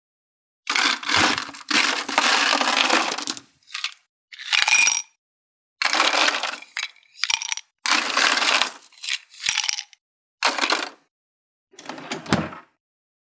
41.氷をコップに入れる【無料効果音】
ASMR/ステレオ環境音各種配布中！！
ASMRコップ効果音